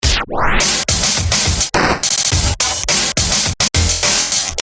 描述：同样的节奏，不同的特效。
Tag: 105 bpm Glitch Loops Drum Loops 787.67 KB wav Key : Unknown